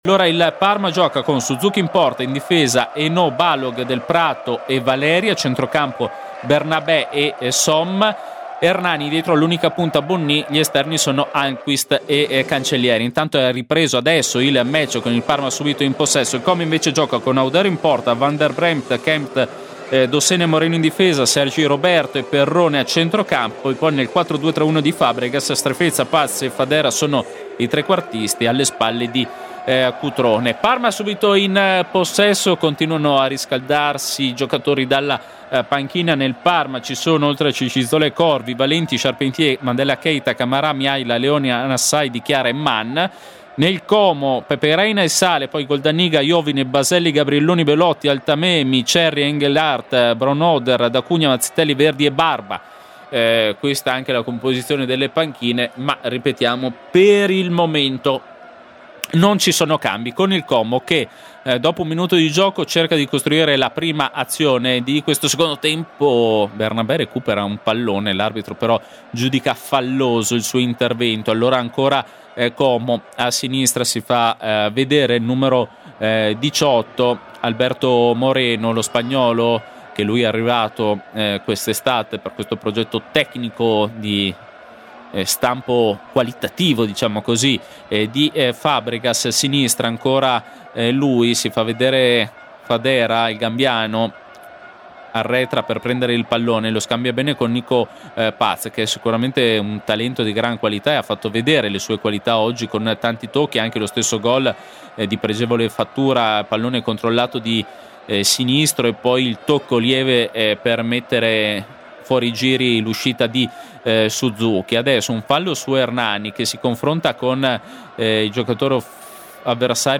Radiocronaca